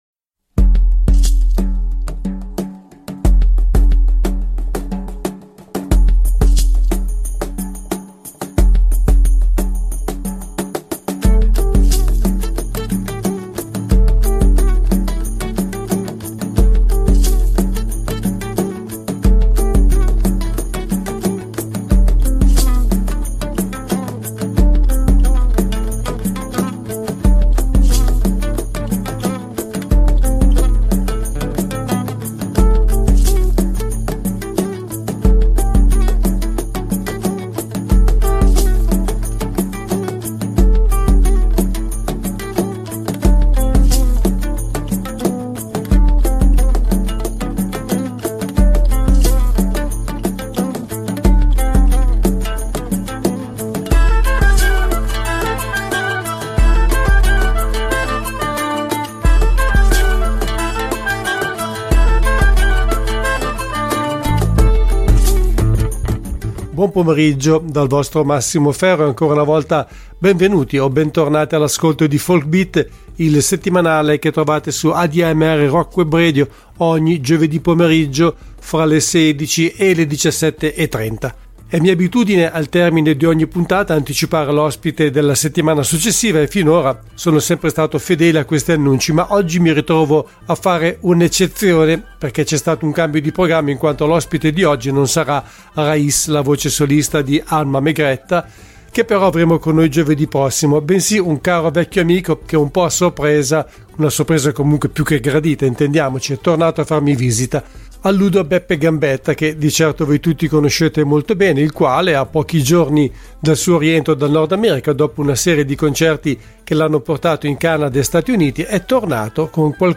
Back Part I: �Folk Beat� (06.04.2023) Ospite del programma al telefono